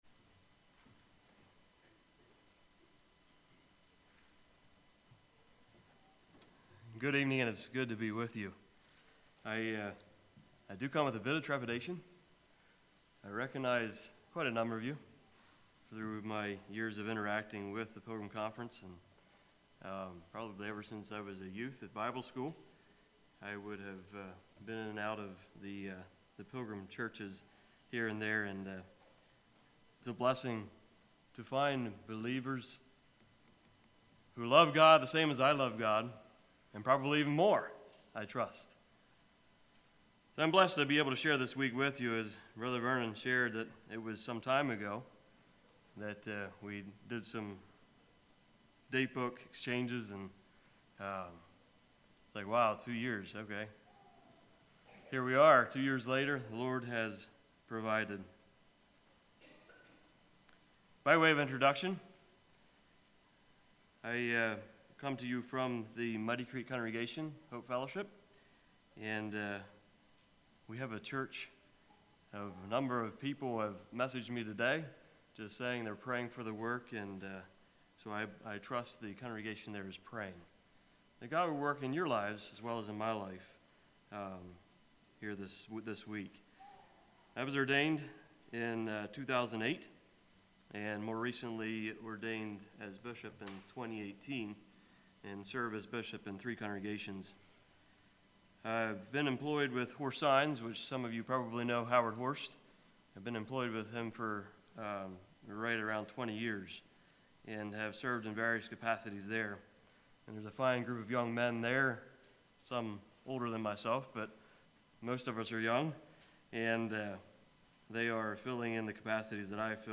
2022 Sermon ID